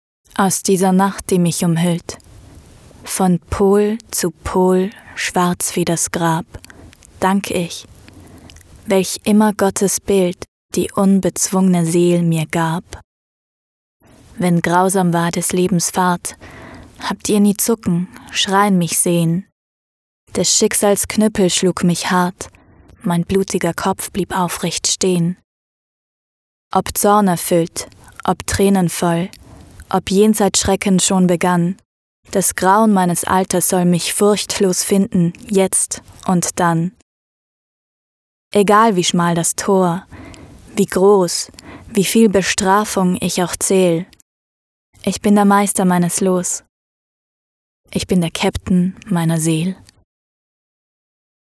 Speaker
Modersmålstalare